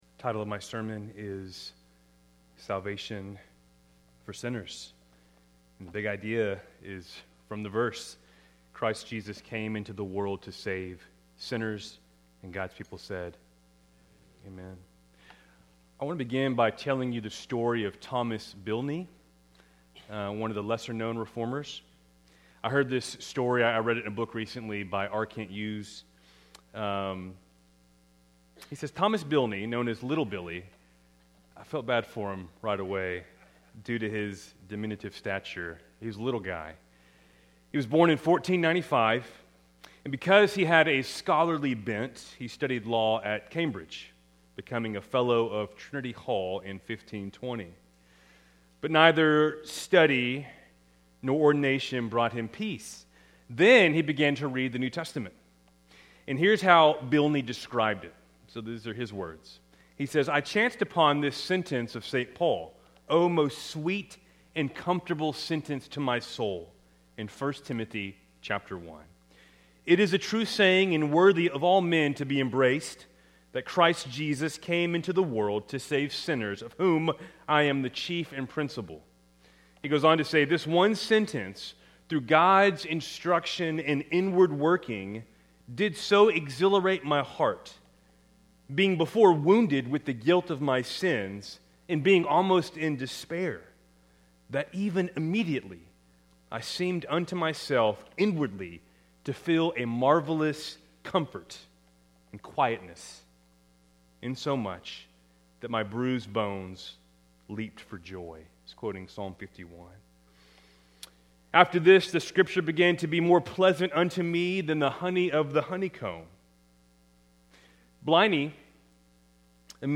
Good Friday Keltys Worship Service, April 3, 2026